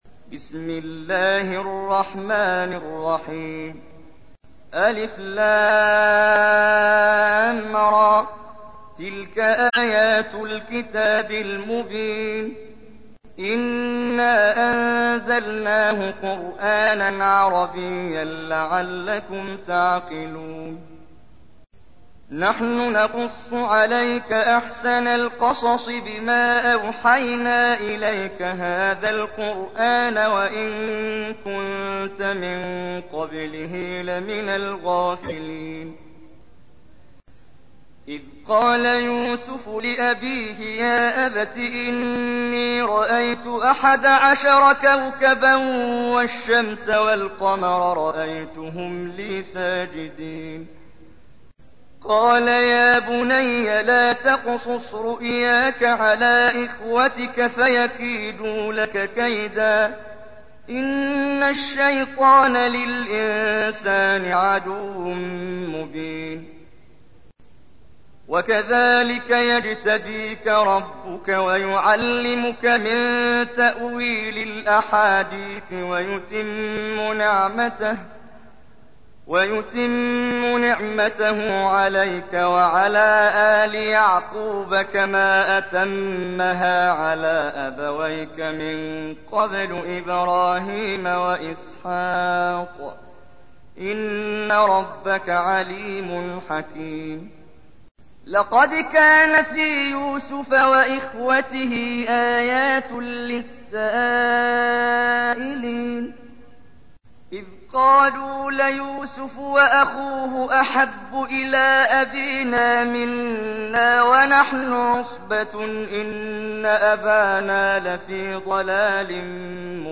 تحميل : 12. سورة يوسف / القارئ محمد جبريل / القرآن الكريم / موقع يا حسين